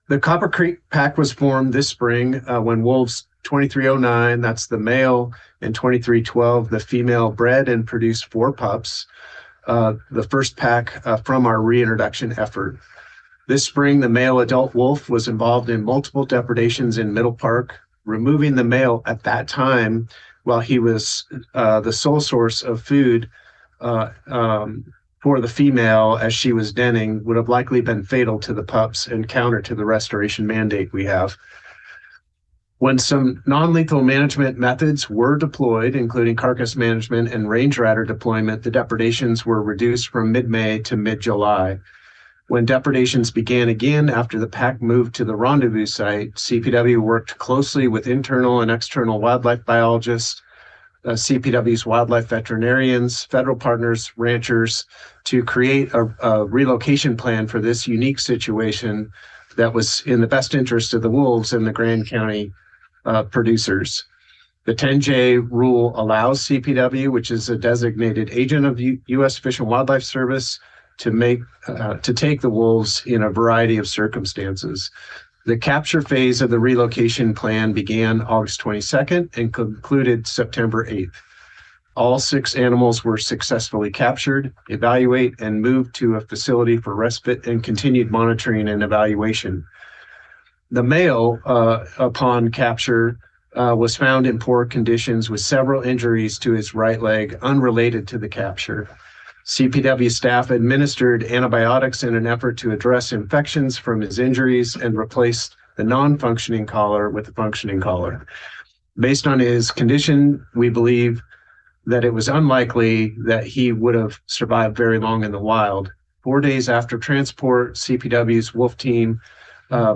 cpw-wolf-webinar-sept-9-2024.mp3